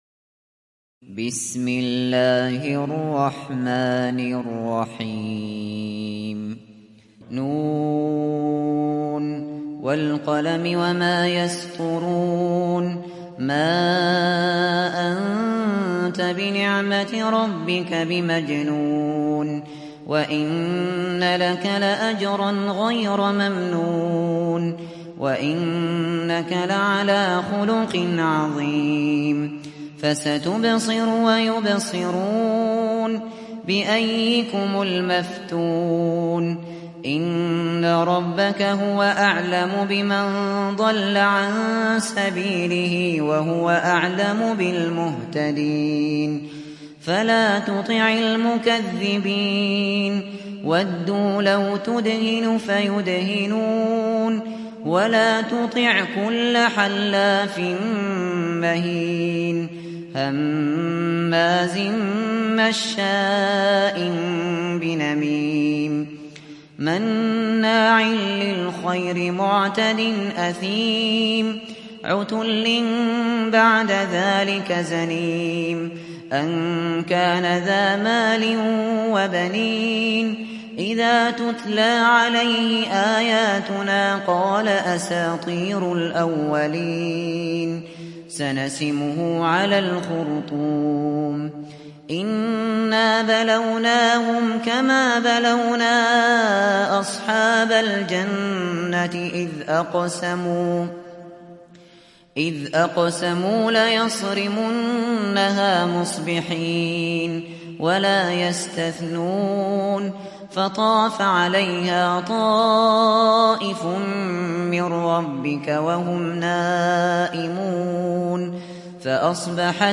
دانلود سوره القلم mp3 أبو بكر الشاطري روایت حفص از عاصم, قرآن را دانلود کنید و گوش کن mp3 ، لینک مستقیم کامل